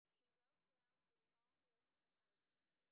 sp11_street_snr0.wav